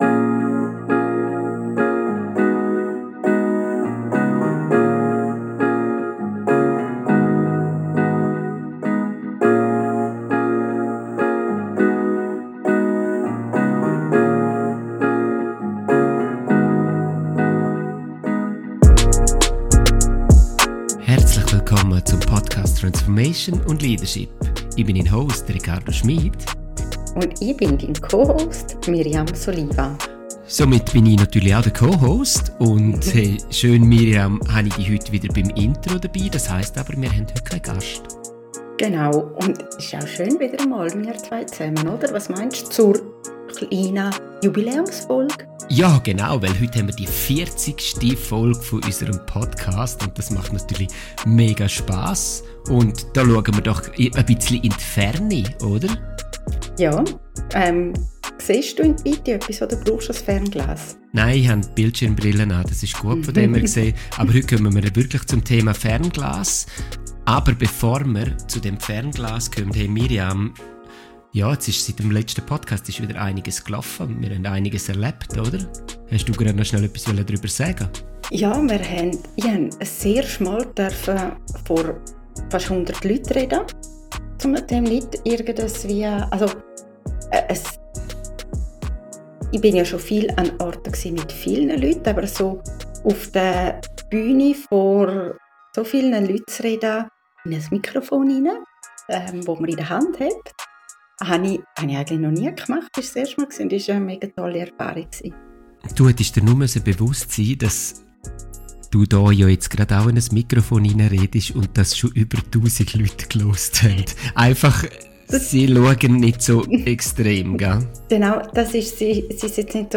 #40 Durch welches Fernglas schaust du? (Schweizerdeutsch)
Ein Gespräch.